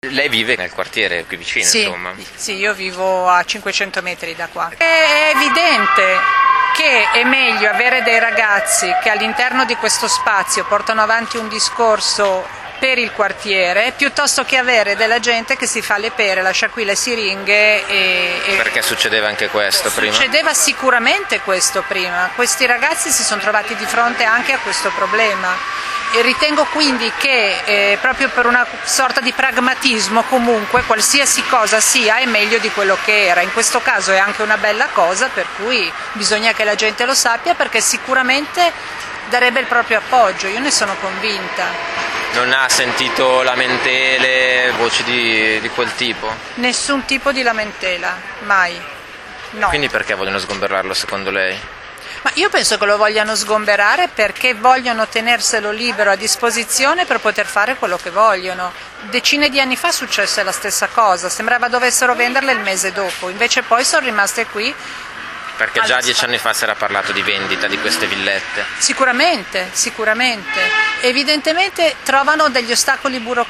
Ieri pomeriggio, al fresco dell’ombra degli alberi di Lambretta, 120 persone si sono parlate, confrontate, conosciute…tutte lì richiamate dall’appello contro il possibile sgombero del quadruplo spazio sociale Lambretta.
voci-quartiere-pro-lambretta.mp3